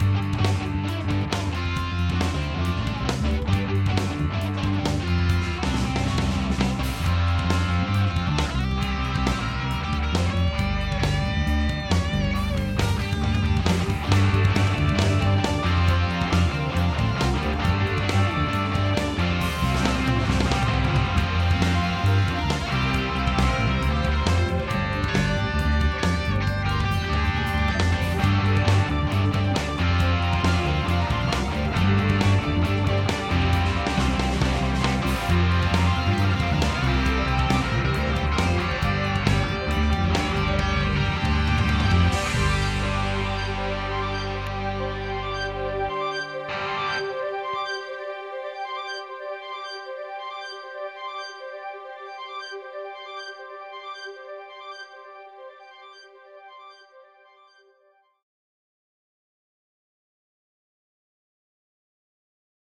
drum, bass and the rest ... studio test - August 2011